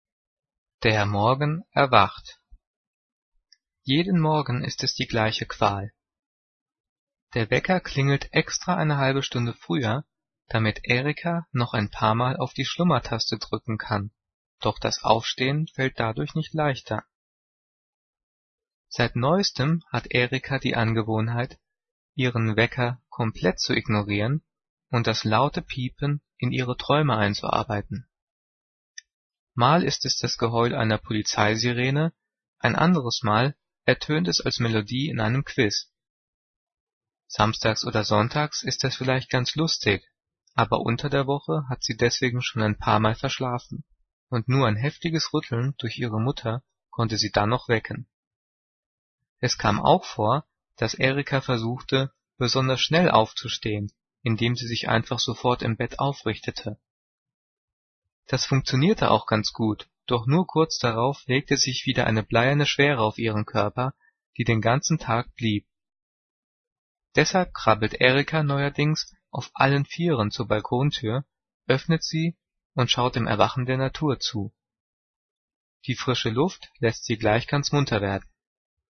Gelesen: